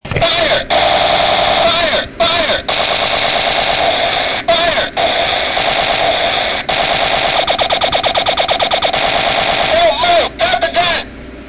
The man communicating to me must be rather trigger-happy, because without assessing the situation, he immediately unstructed me to "fire! fire! fire! fire!" and he intervened with sounds of machine gun fire to help confuse my attackers. Then at the end, he told me to not move and drop the gun.